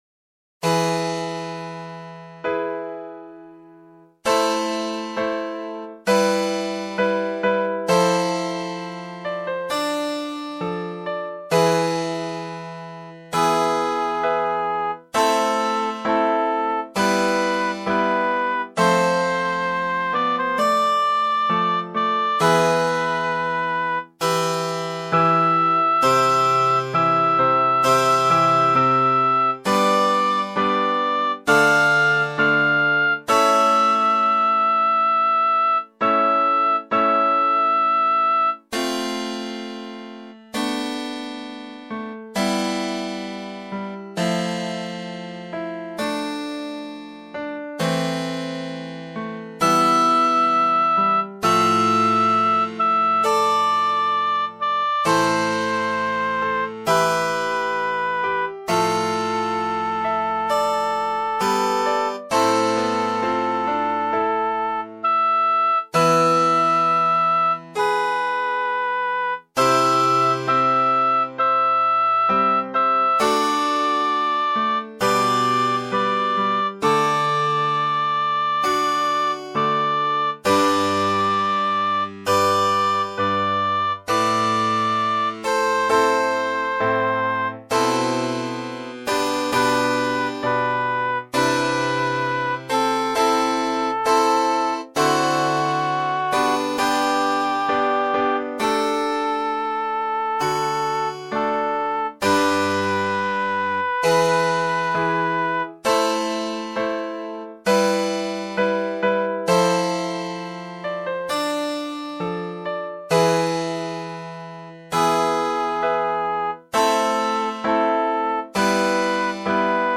Ch�ur